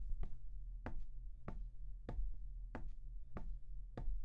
多种声音 " 步骤1
描述：只是脚步声
Tag: 散步 脚步声